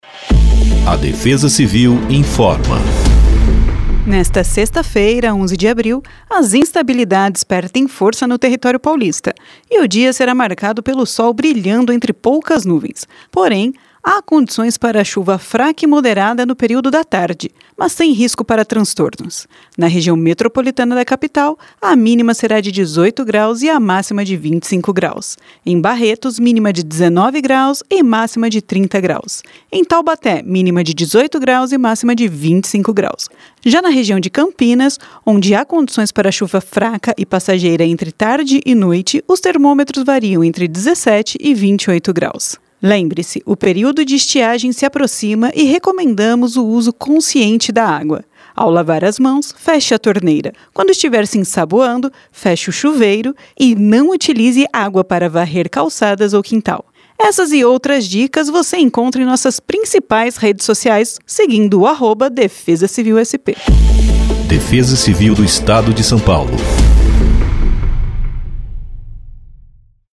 Defesa-Civil-Boletim-Previsao-do-Tempo-para-1104-Spot.mp3